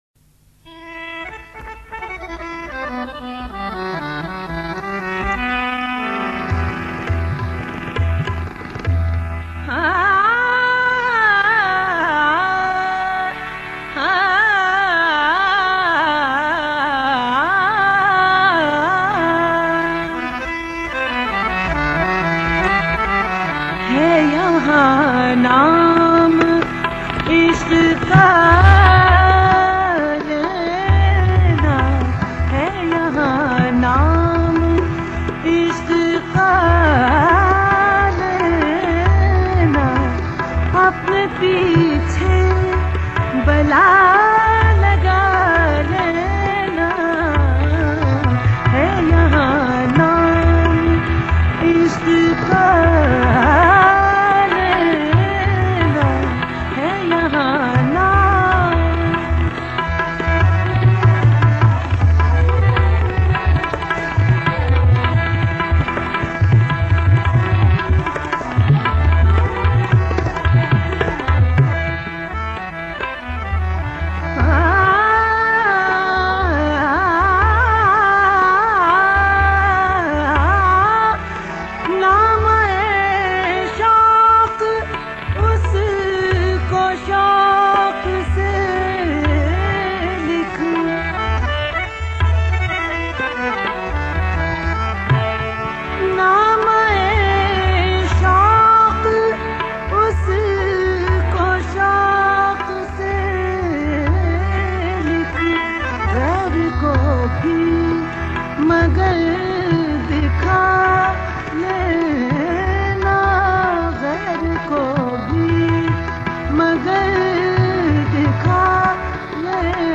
A Ghazal
in Jangla Bhairavi
harmonium